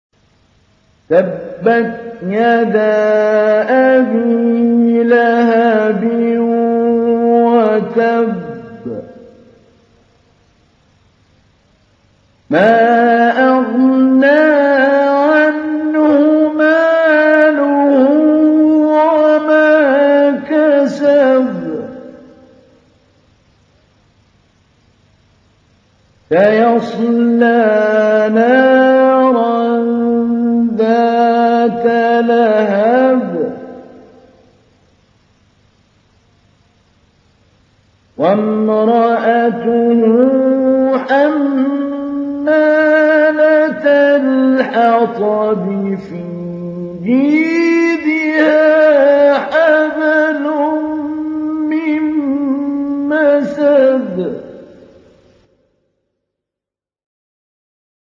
تحميل : 111. سورة المسد / القارئ محمود علي البنا / القرآن الكريم / موقع يا حسين